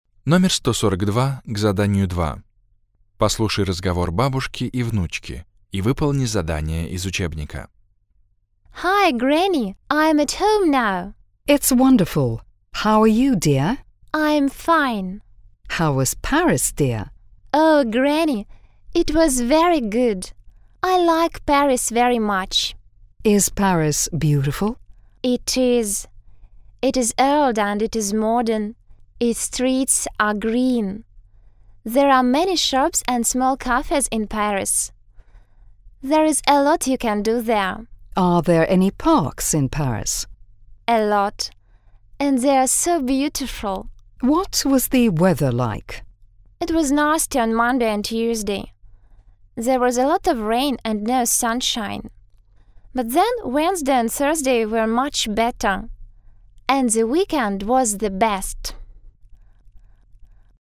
2. Сара рассказывает по телефону бабушке о своей поездке в Париж.